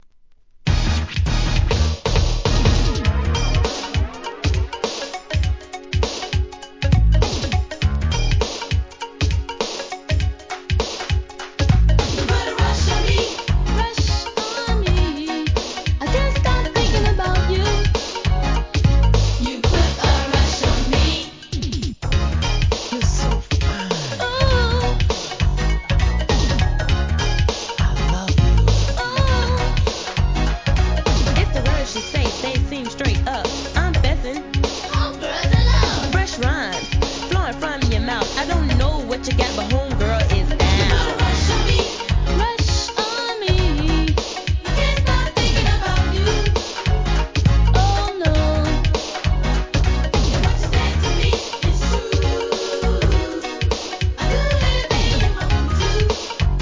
NEW JACK SWING!